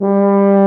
BRS TUBA F0N.wav